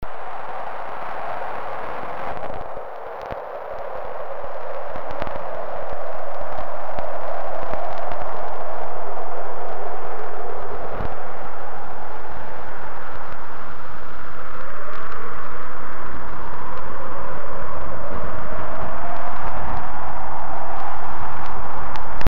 I excitedly walked down to the docks and mounted SeaSong in the usual way, then left it there for about 8 hours.